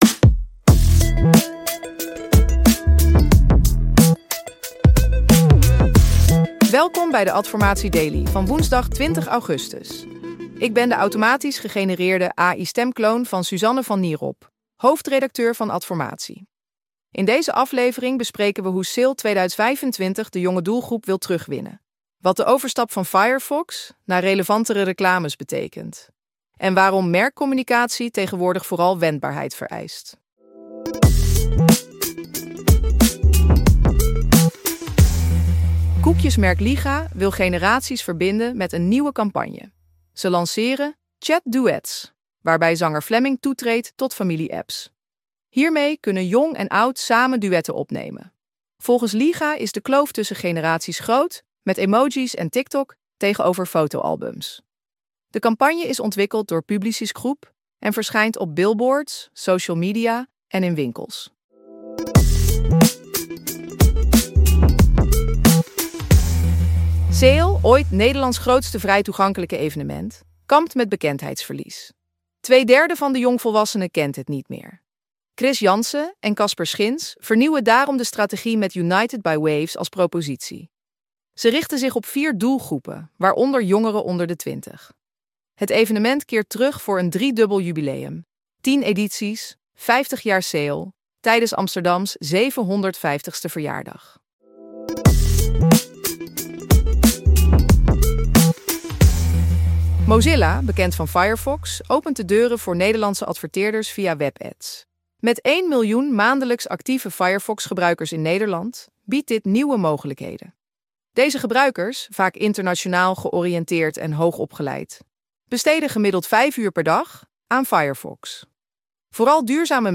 Geïnteresseerd in een automatisch gegenereerde podcast voor jouw content?